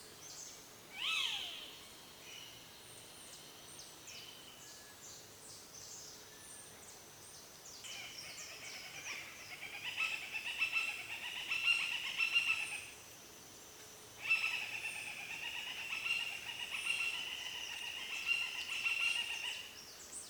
CARRAPATEIRO
Outros nomes populares:  Gavião-pinhé ou pinhé (por causa do canto característico), caracará-branco, caracaraí, caracaratinga, carapinhé, chimango, papa-bicheira, , chimango-branco e chimango-carrapateiro e chimango-do-campo e gavião-carrapateiro.
Quando em sobrevôo, emite um grito agudo que soa como “pinhé”, semelhante ao canto do gavião-carijó (Rupornis magnirostris).